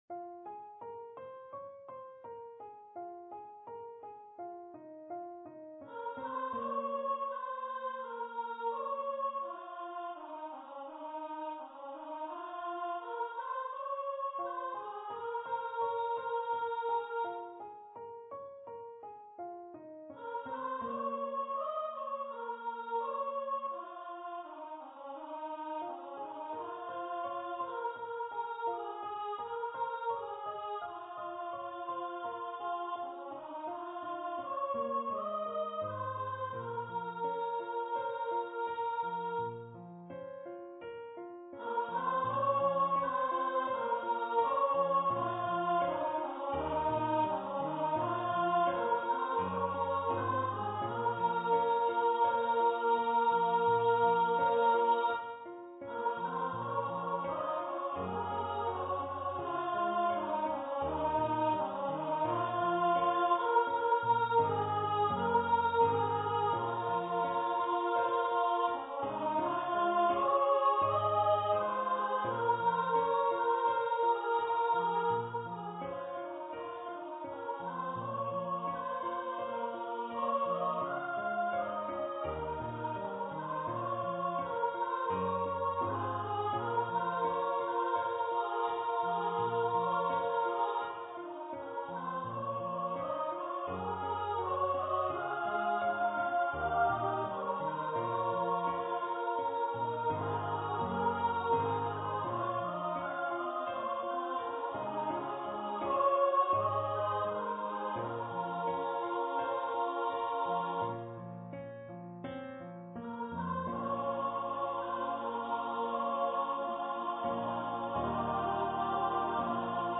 for female voice choir and piano
Choir - 3 part upper voices